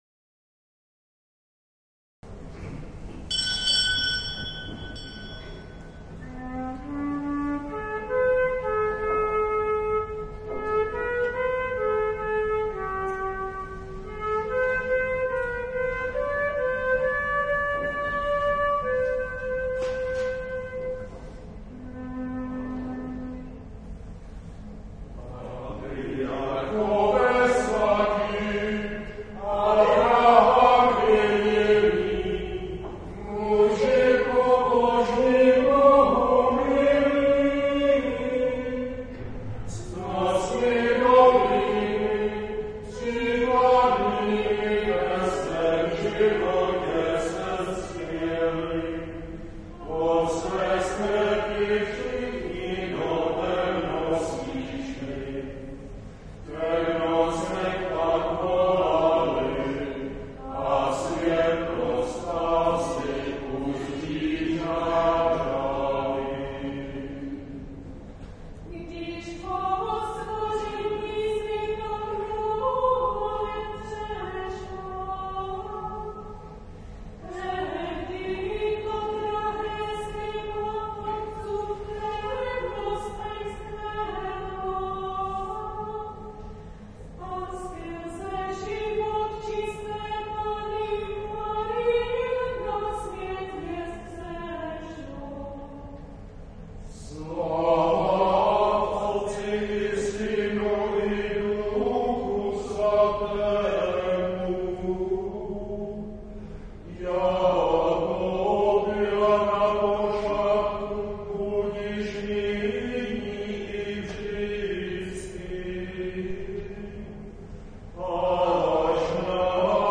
Roráty, které se každý adventní čas na kůru svatováclavského kostela ve Voticích během ranních adventních mší zpívají, jsou pro tyto odstíny vánočního poselství dosti vnímavé.